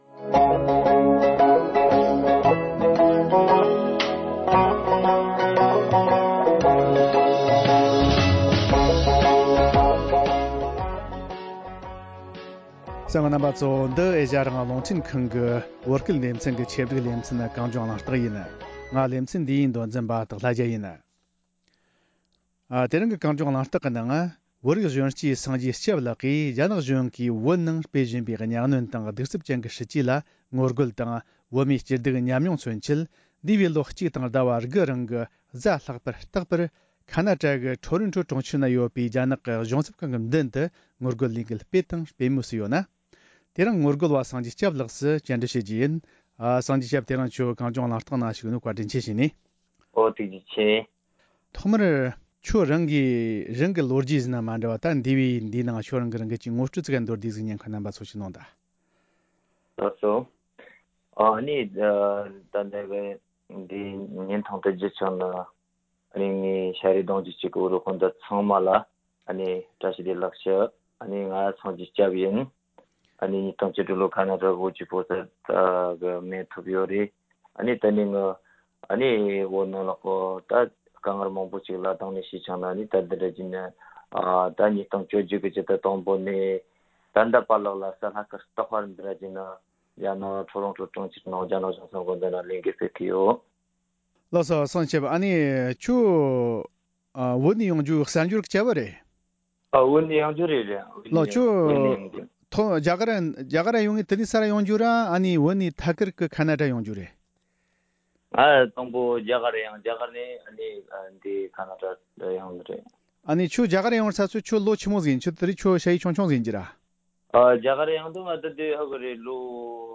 བཀའ་མོལ